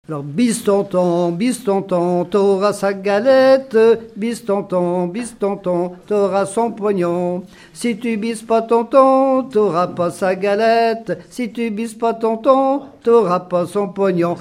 L'enfance - Enfantines - rondes et jeux
Pièce musicale inédite